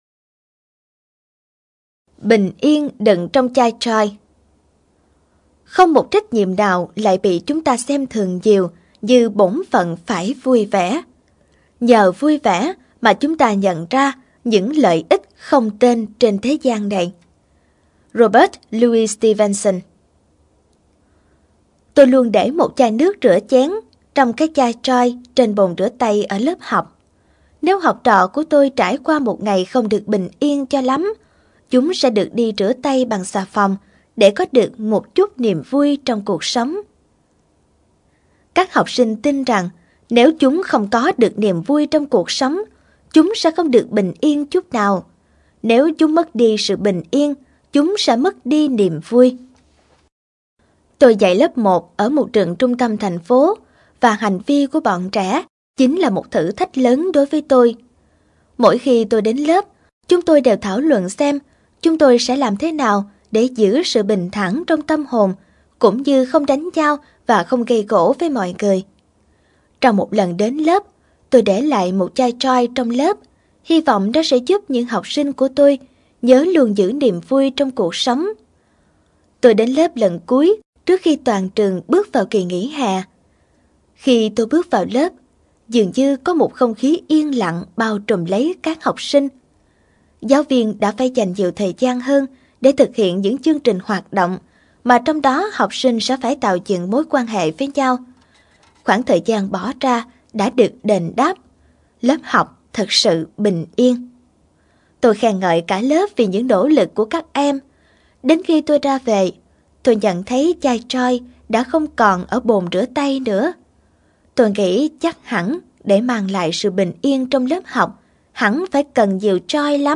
Sách nói Chicken Soup 19 - Tin Vào Ngày Mai - Jack Canfield - Sách Nói Online Hay